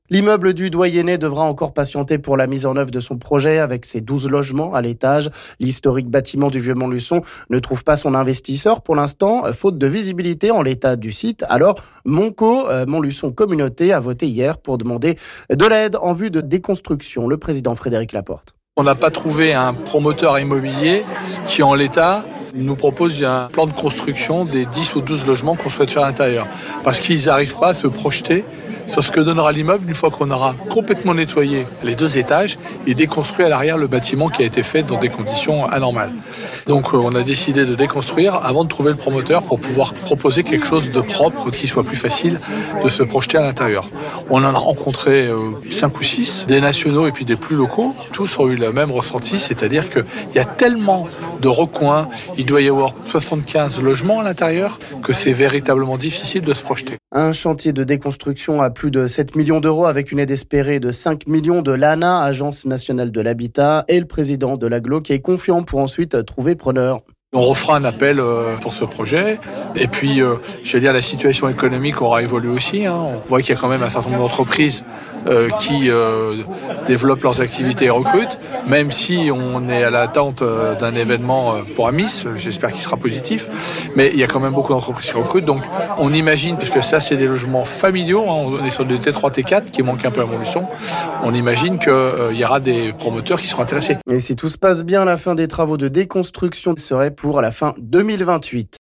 On écoute le président de l'agglo Frédéric Laporte...